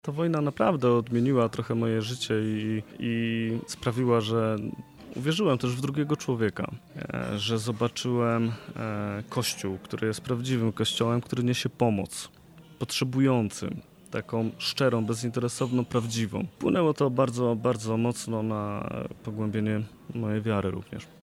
Nadajemy program prosto z Dworca Głównego PKP we Wrocławiu, z kawiarni Stacja Dialog.